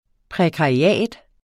Udtale [ pʁεkɑiˈæˀd ]